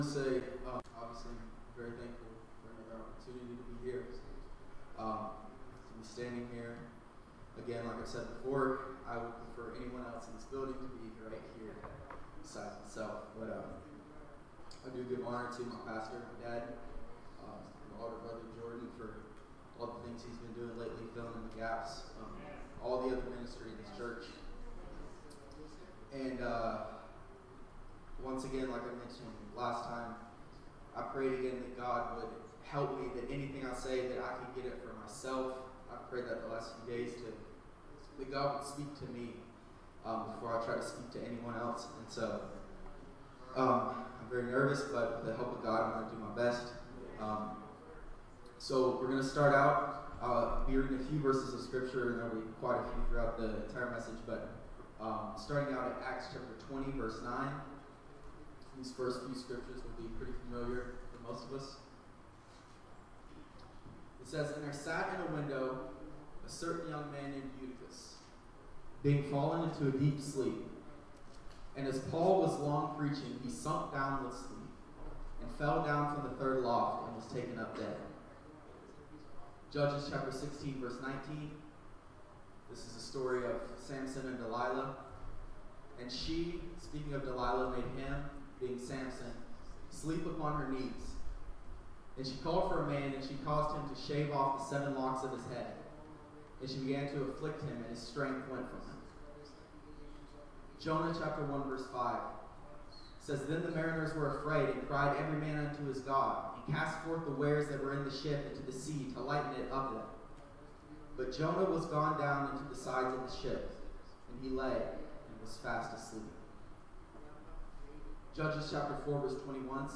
First Pentecostal Church Preaching 2019